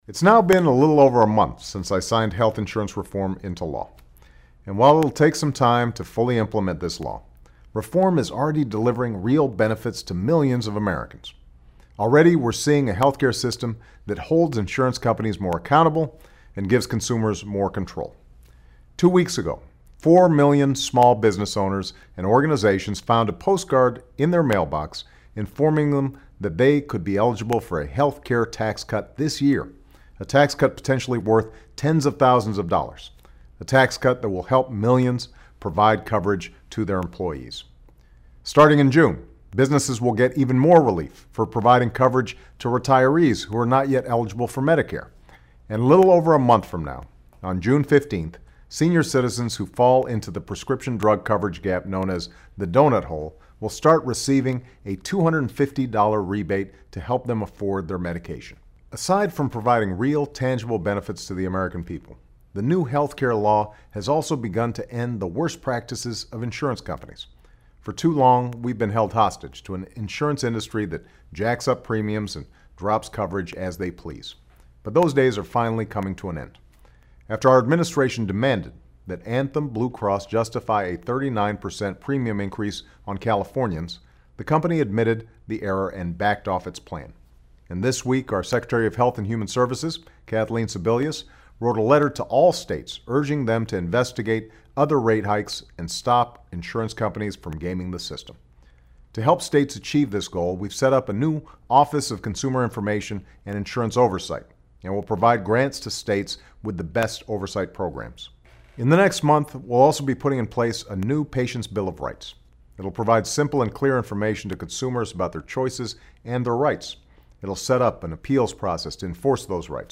Declaraciones del Presidente Barack Obama
Mensaje semanal